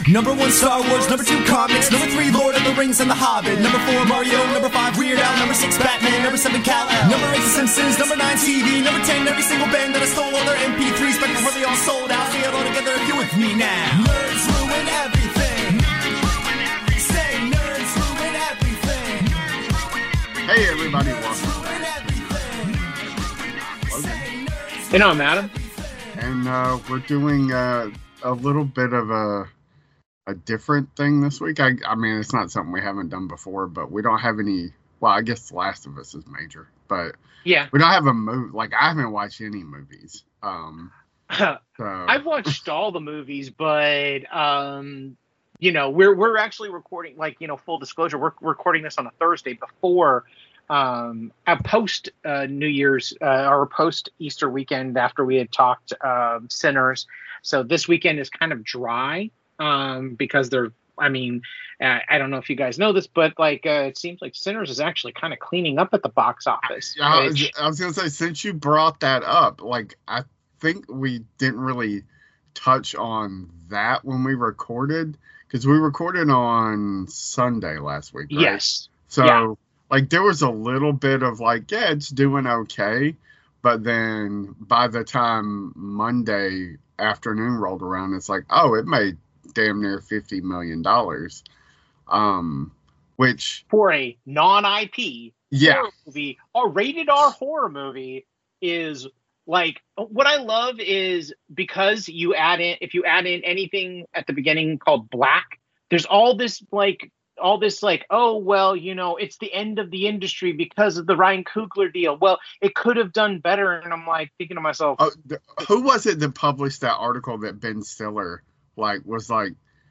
two nerds